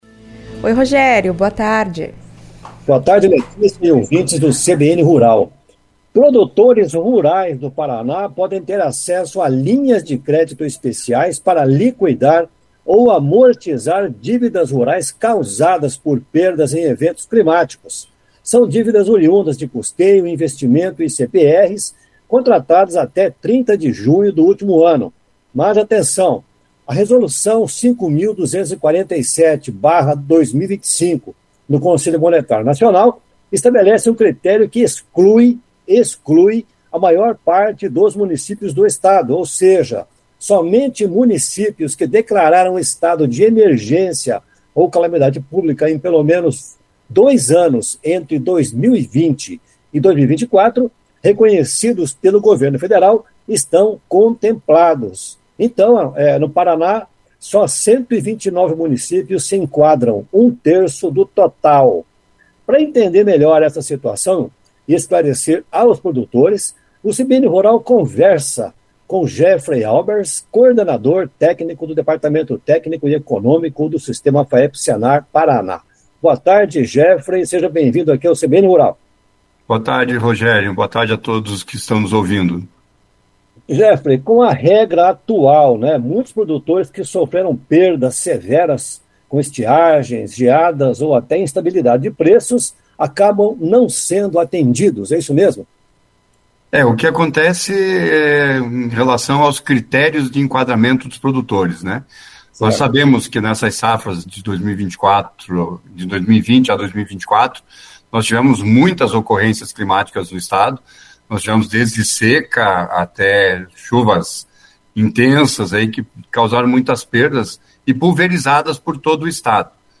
Para entender melhor essa situação e esclarecer aos produtores, o CBN Rural conversou com